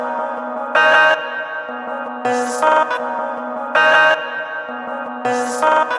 描述：同样的声乐剪辑循环
标签： 160 bpm Trap Loops Vocal Loops 1.01 MB wav Key : Unknown
声道立体声